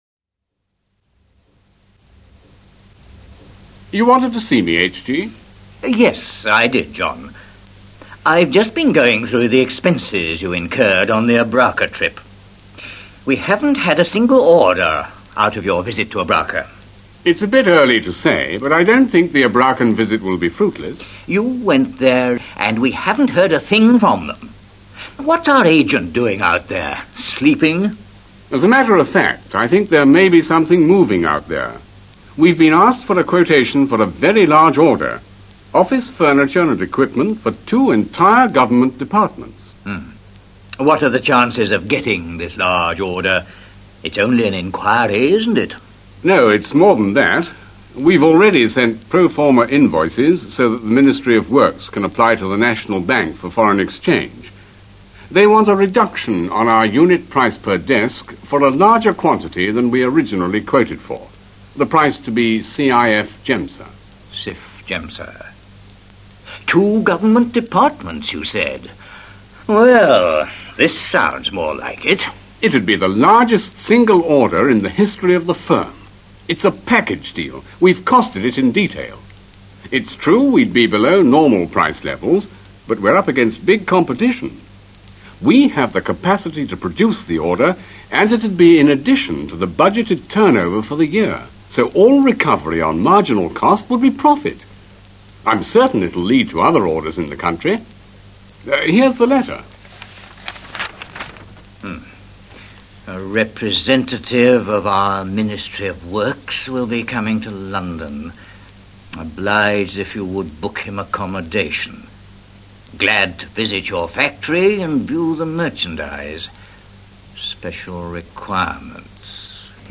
conversation13.mp3